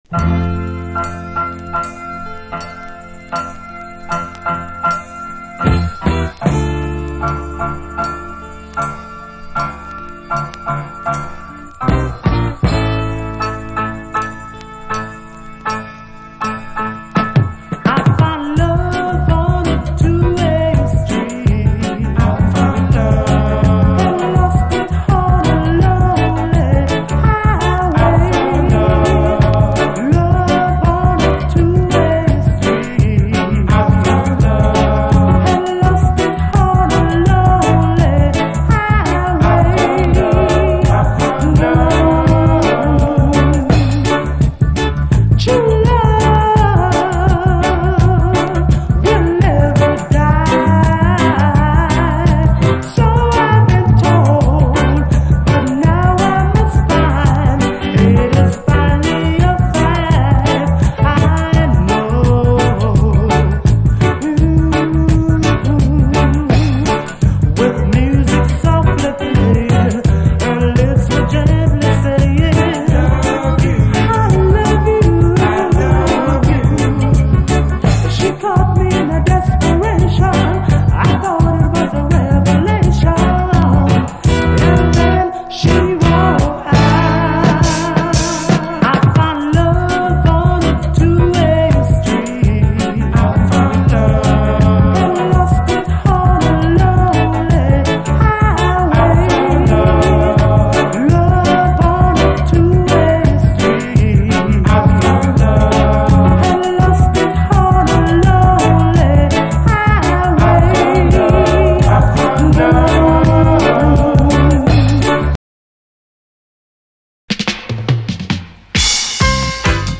ジャマイカン〜UK混合のラヴァーズ・ロック・コンピレーション盤！
モコモコのベースがガツンとファンキー、アンノウンでオブスキュアなキラー・ヴァージョン！